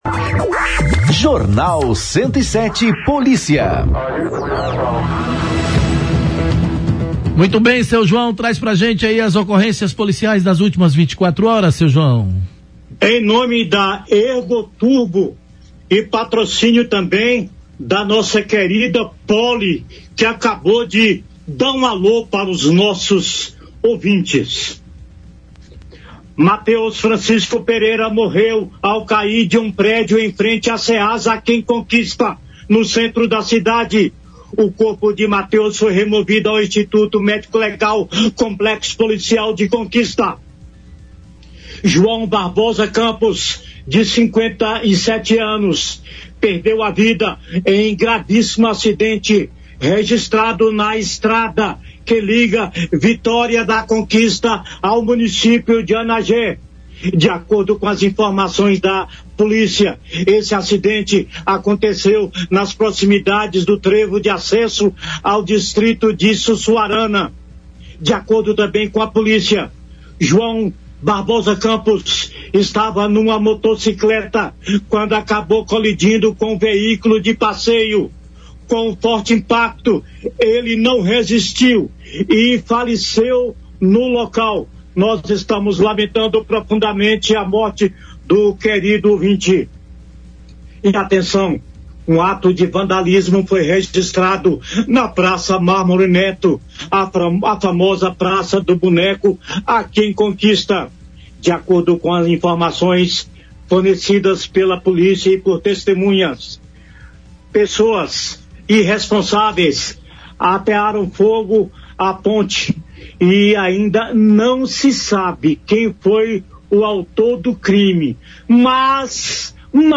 As Ocorrências Policiais do Jornal 107 desta quinta-feira (26) trazem um panorama completo dos principais fatos registrados em Vitória da Conquista nas últimas 24 horas.